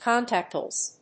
/ˈkɑntæˌktlɛs(米国英語), ˈkɑ:ntæˌktles(英国英語)/